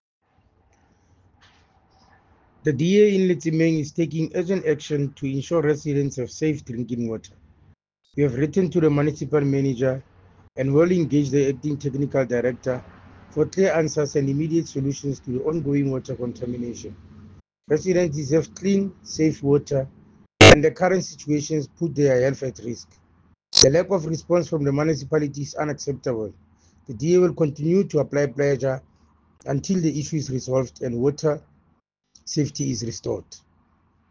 English soundbite by Cllr Thabo Nthapo,